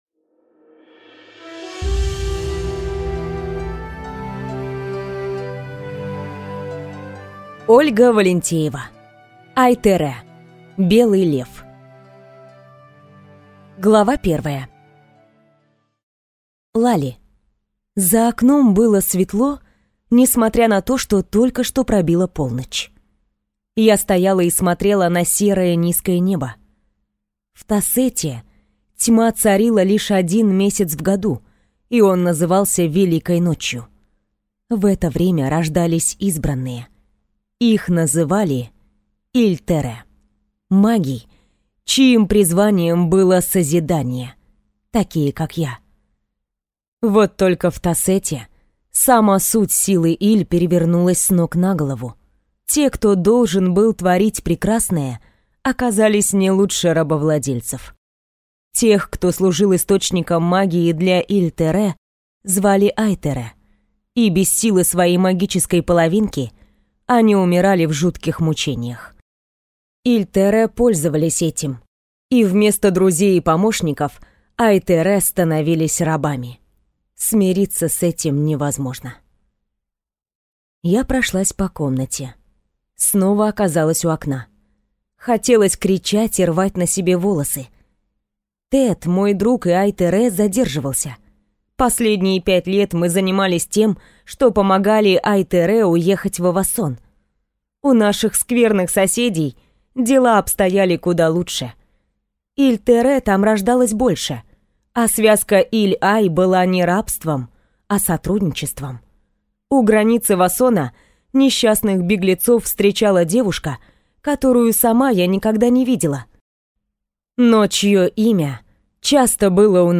Аудиокнига Ай-тере. Белый лев | Библиотека аудиокниг